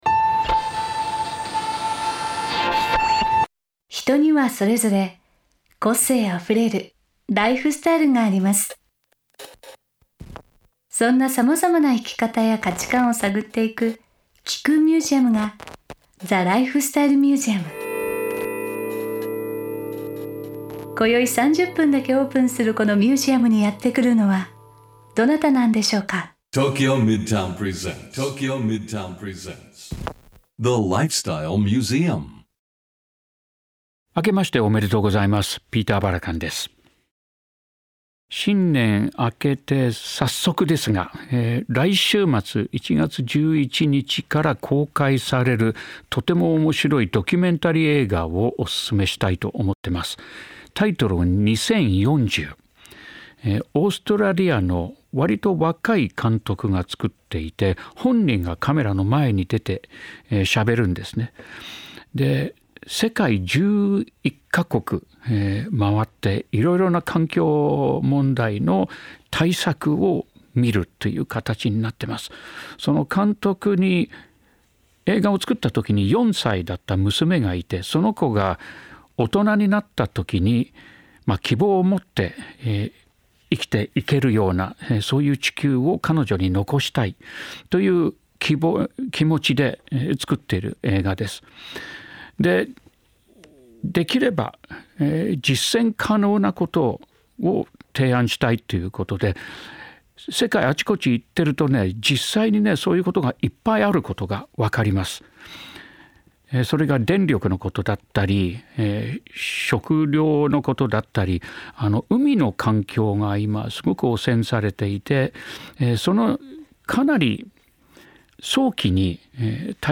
ピーター・バラカン氏がメインパーソナリティーとなり、毎回様々なゲストを迎えて生き方や価値観を探っていくゲストトーク番組。